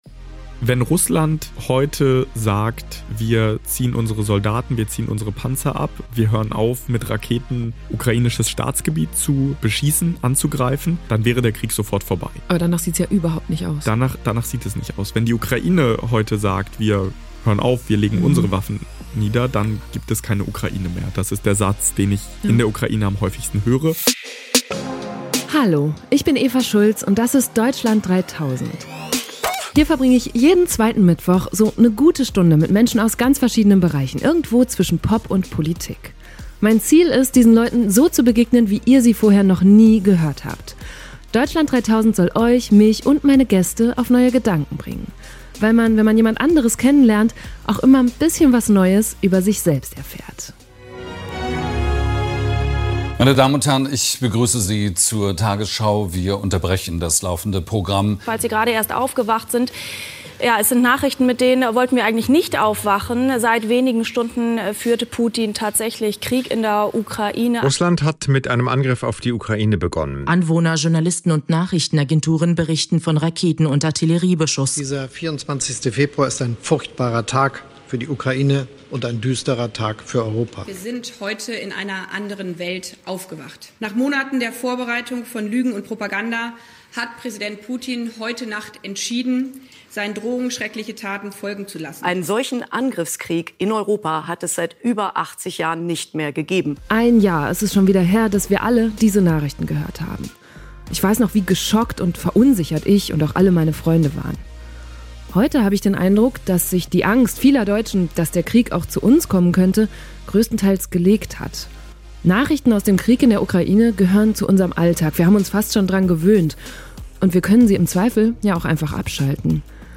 Dieser Teil des Interviews hat mich persönlich sehr beeindruckt.